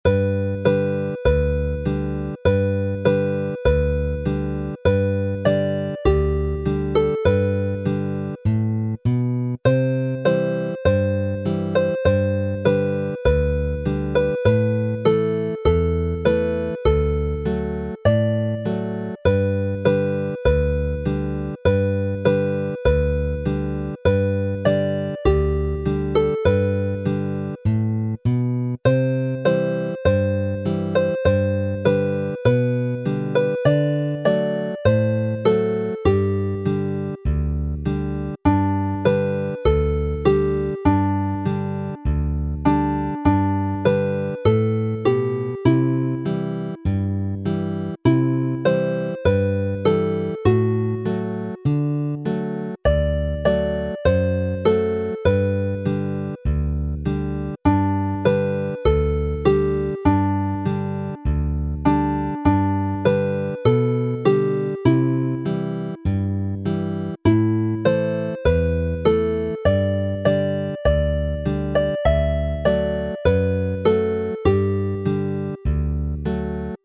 Chwarae'n araf
Play slowly